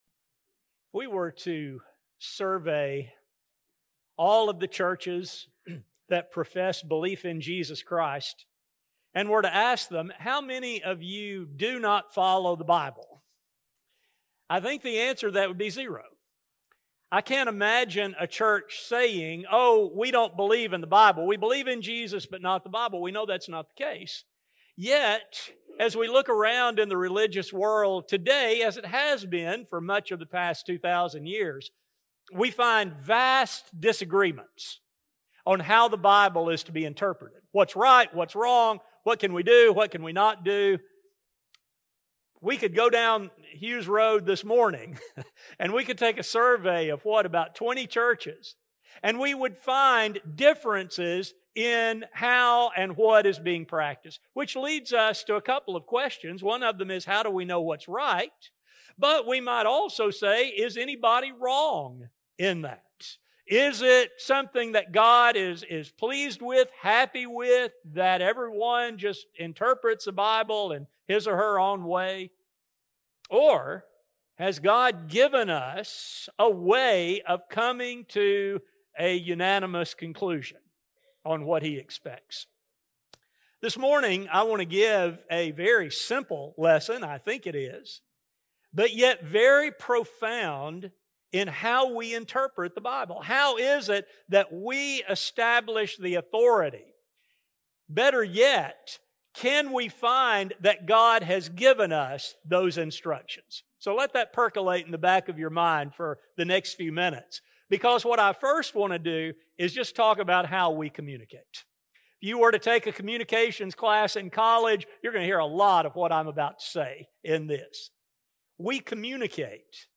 This study will focus on the teachings of the New Testament in relation to its interpretation. A sermon